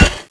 BITFALL/knife_hitwall2.wav at ba9f4eda804a3ba272fcf5cd7d3128d05bbf01aa
knife_hitwall2.wav